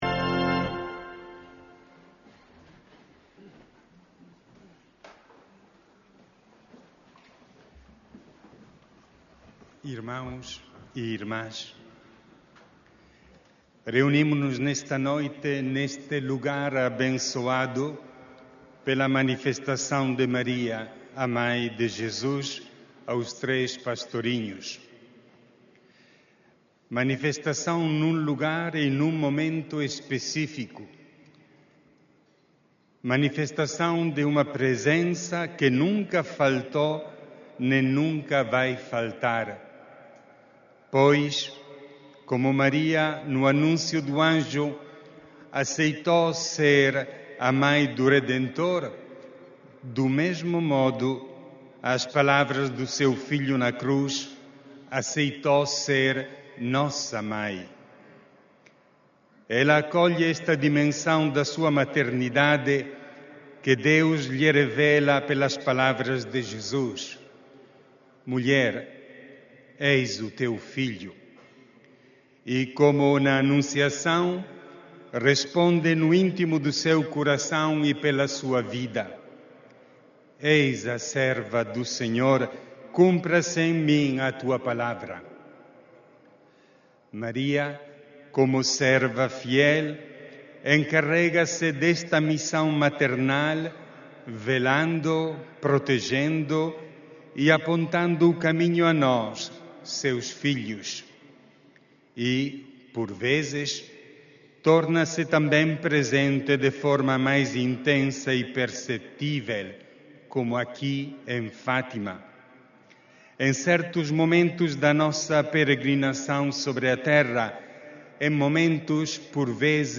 Um caminho para a paz, por via de Nossa Senhora, foi delineado pelo arcebispo de Beira, Moçambique, esta noite, no Santuário de Fátima, na homilia da celebração da Palavra da Peregrinação Internacional Aniversária de 12 e 13 de outubro.
Áudio da homilia de D. Claudio Dalla Zuanna